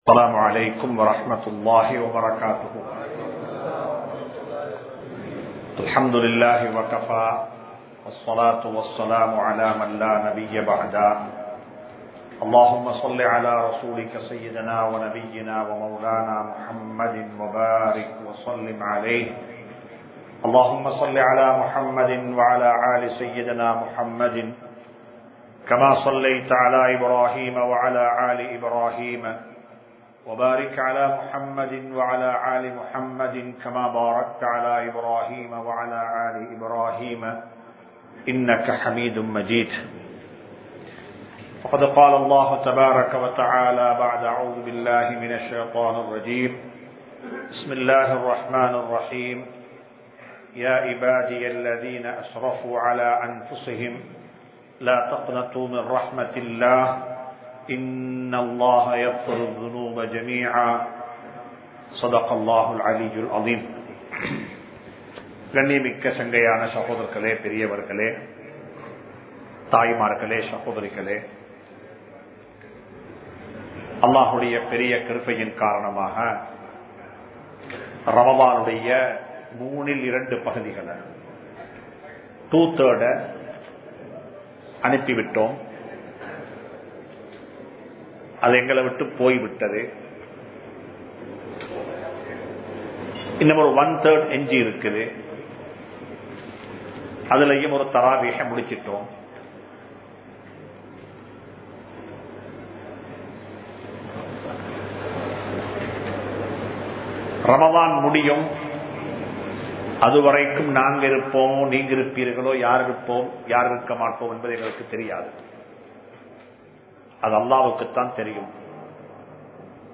தௌபாவும் மனிதனும் | Audio Bayans | All Ceylon Muslim Youth Community | Addalaichenai
Muhiyadeen Jumua Masjith